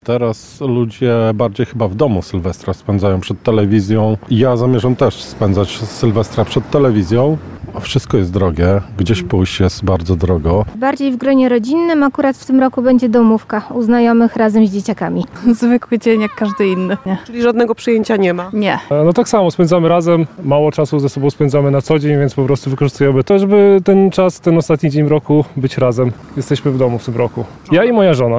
Sprawdziliśmy jak Nowy Rok przywitają mieszkańcy Białej Podlaskiej.